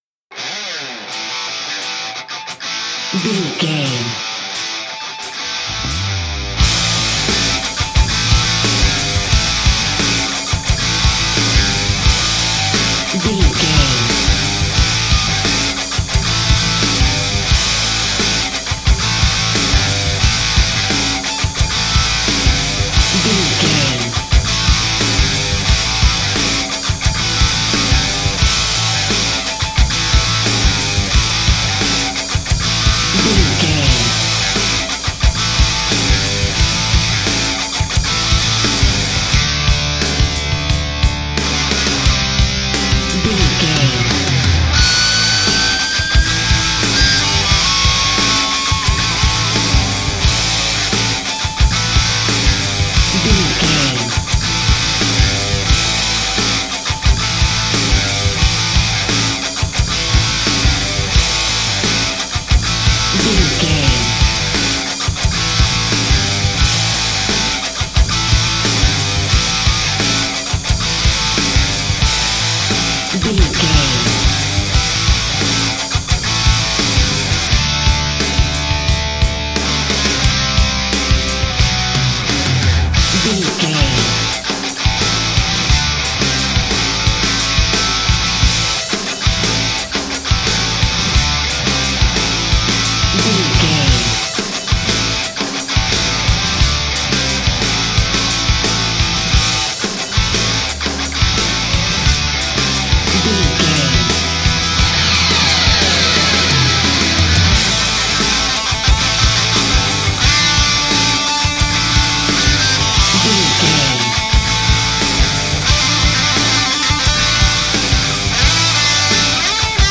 Ionian/Major
drums
electric guitar
bass guitar
hard rock
aggressive
energetic
intense
nu metal
alternative metal